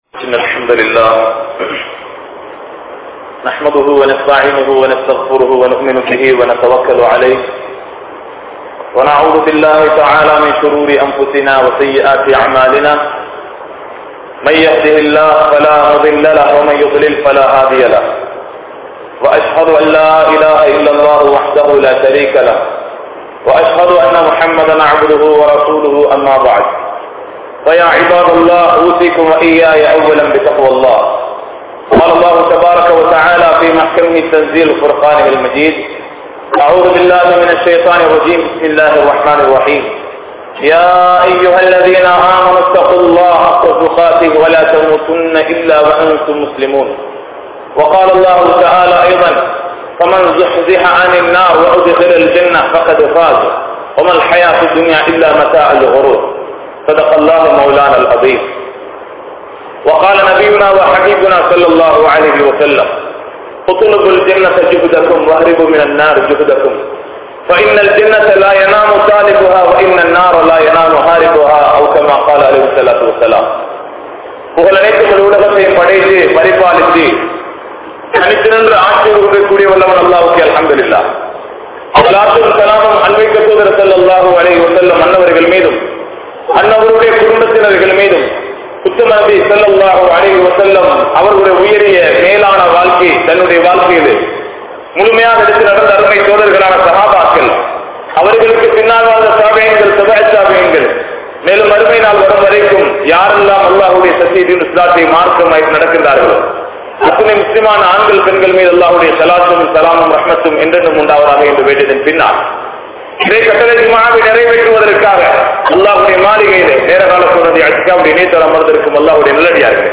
Suvarkatthin Inpagal | Audio Bayans | All Ceylon Muslim Youth Community | Addalaichenai
Majmaulkareeb Jumuah Masjith